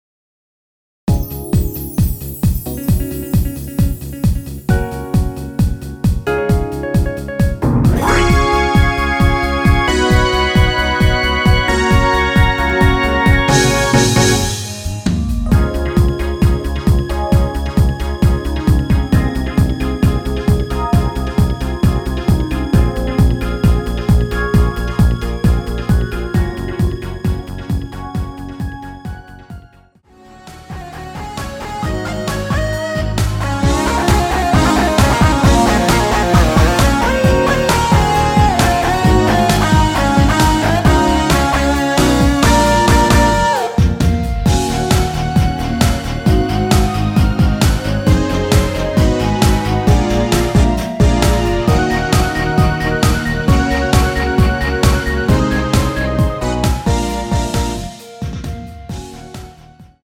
원키에서(-1)내린 멜로디 포함된 MR 입니다.(미리듣기 참조)
Db
앞부분30초, 뒷부분30초씩 편집해서 올려 드리고 있습니다.